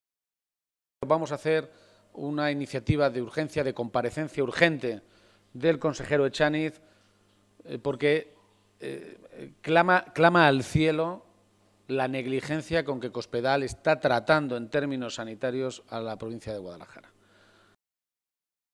Emiliano García-Page durante la rueda de prensa celebrada en Guadalajara